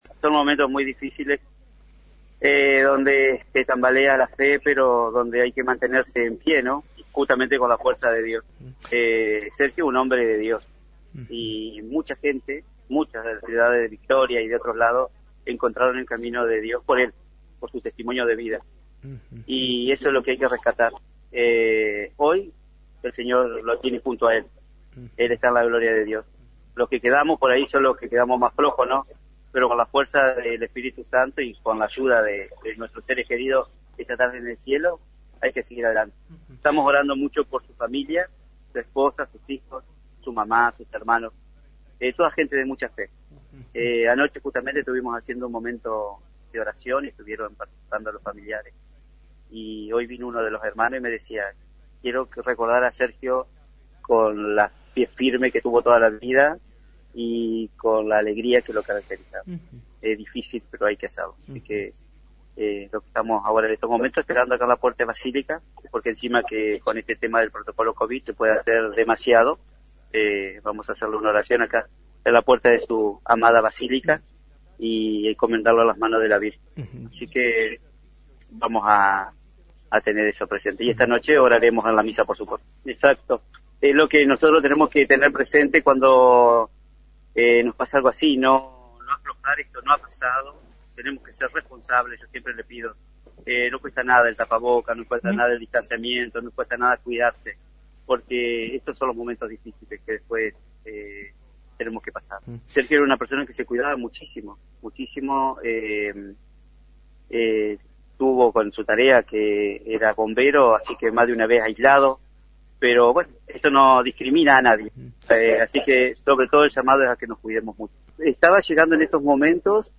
desde el micrófono de FM 90.3.